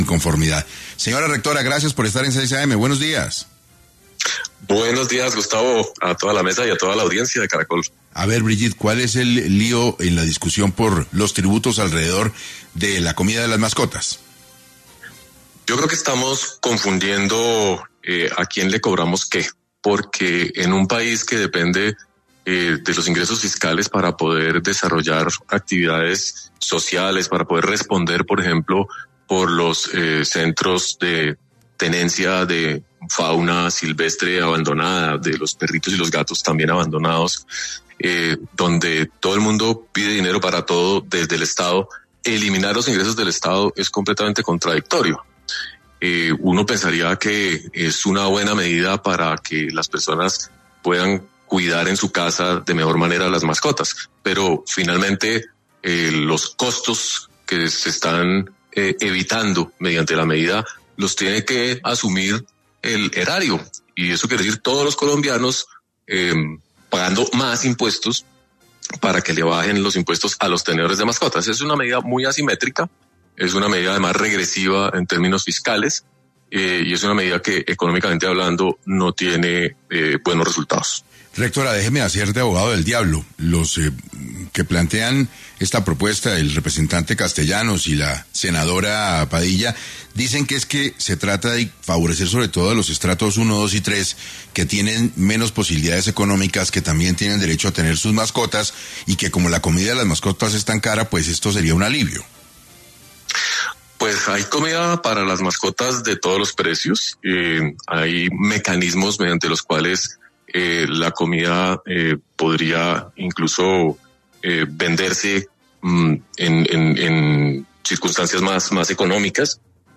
Brigitte Baptiste, rectora de la Universidad EAN, explicó las posibles consecuencias del proyecto que busca eximir del impuesto del IVA el alimento de las mascotas en Colombia.